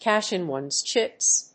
cásh [páss] ín one's chíps
発音